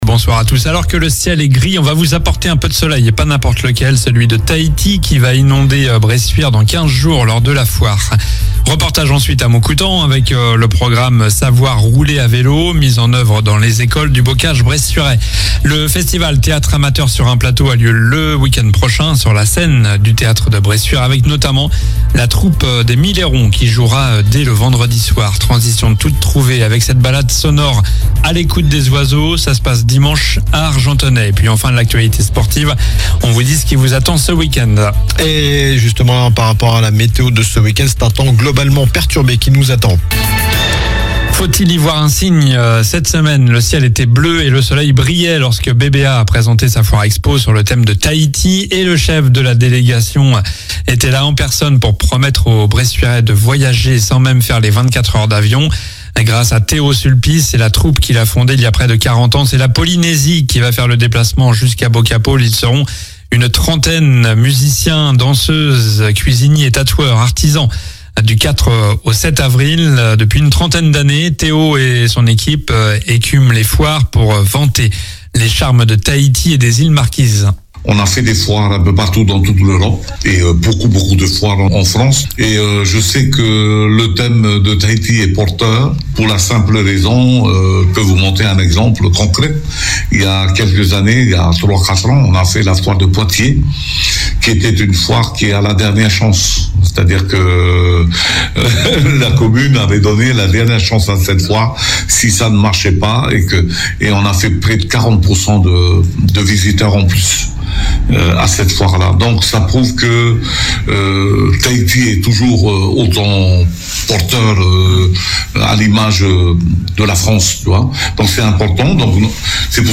Journal du vendredi 21 mars (soir)